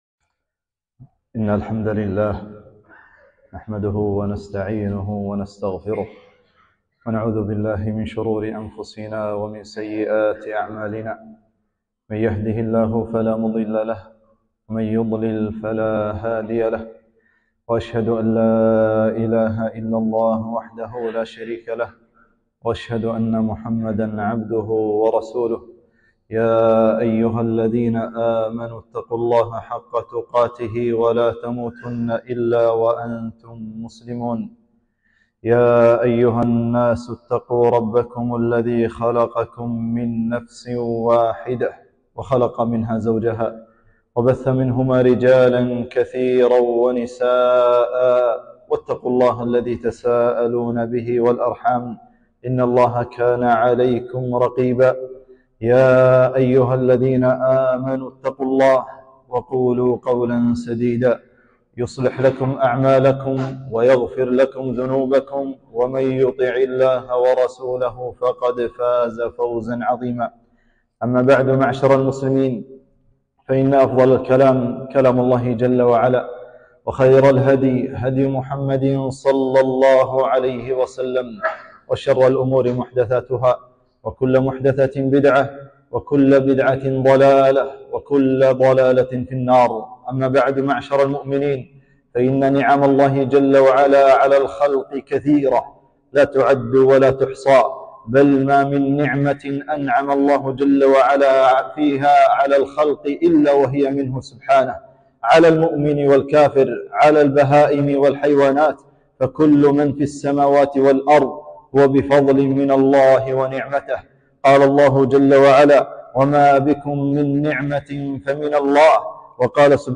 خطبة - شكر النعم وحاله ﷺ في معها